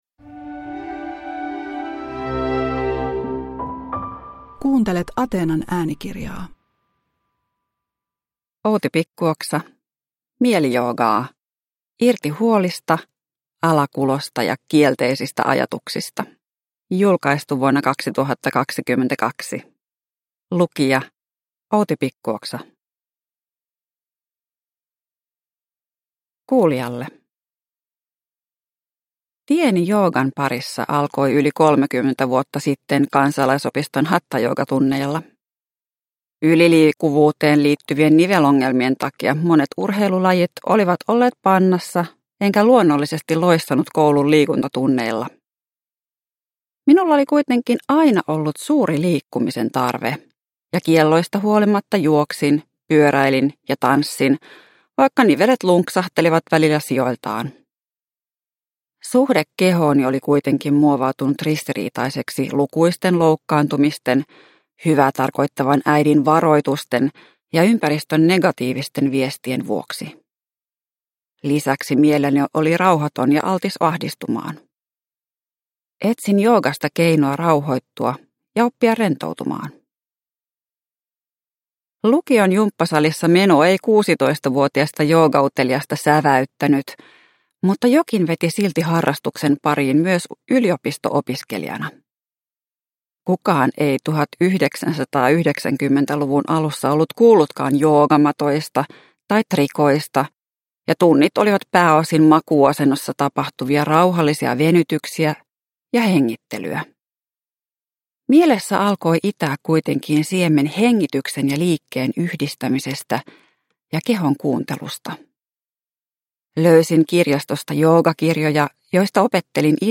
Mielijoogaa – Ljudbok – Laddas ner